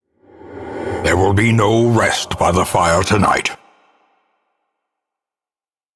vo_narr_bad_flashback_01